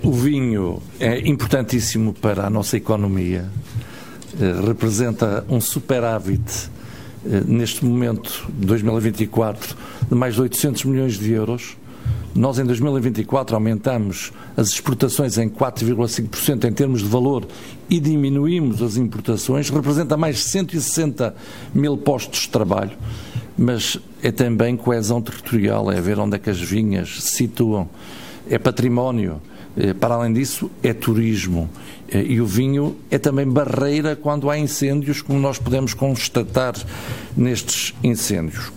Além disso, o ministro sublinha a importância do vinho para a economia nacional: